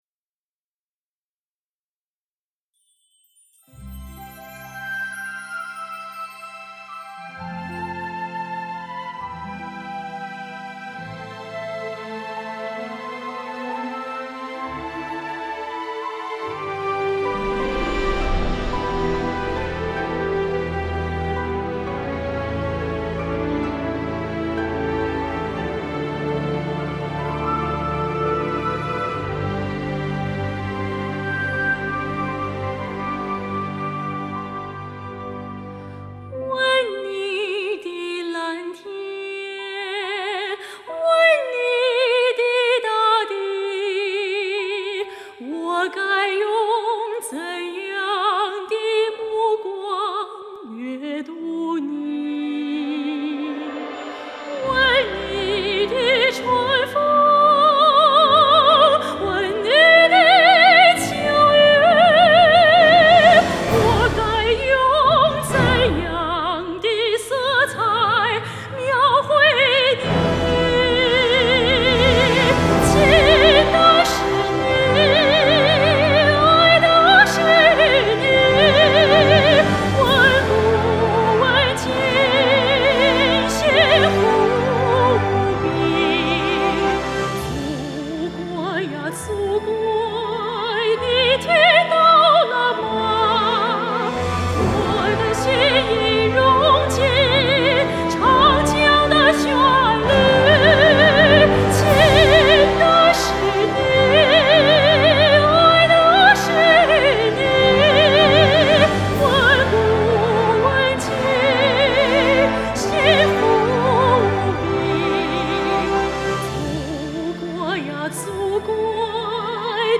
青年旅美女高音